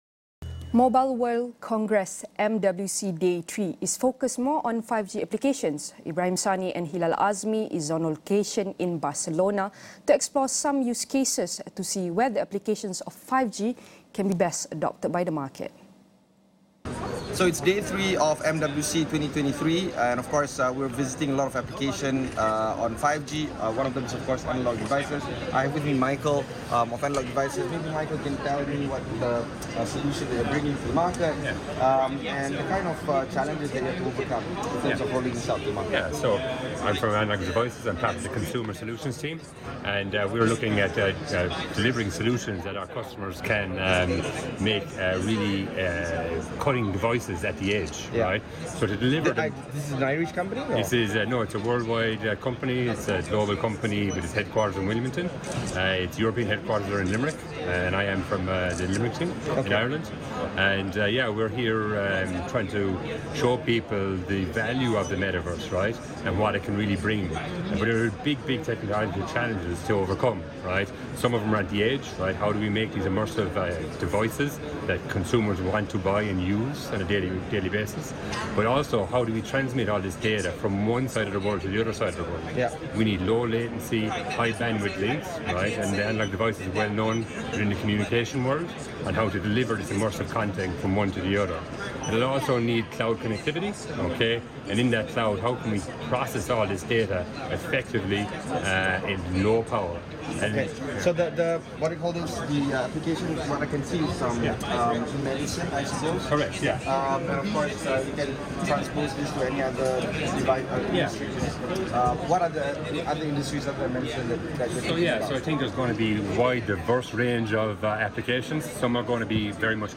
on location in Barcelona